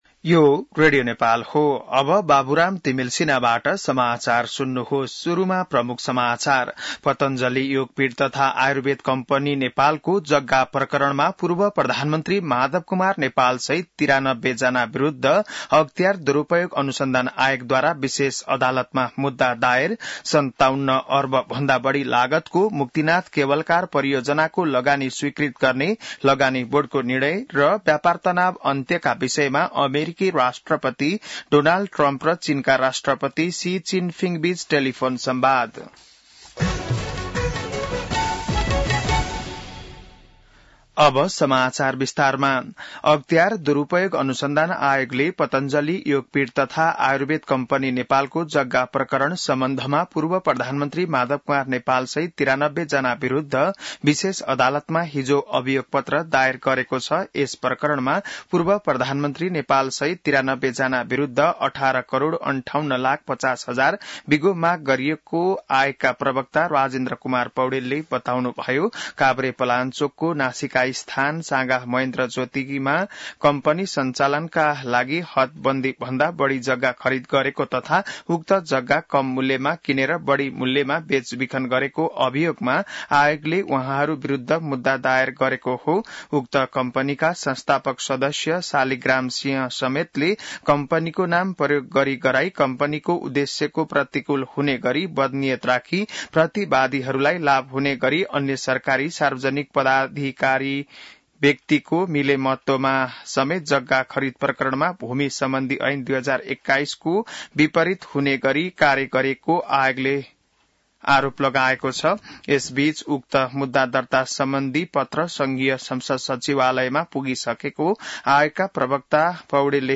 बिहान ९ बजेको नेपाली समाचार : २३ जेठ , २०८२